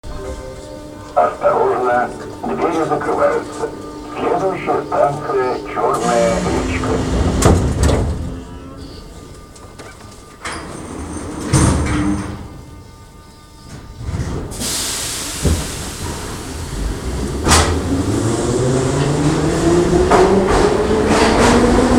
Атмосферный звук метро Санкт-Петербурга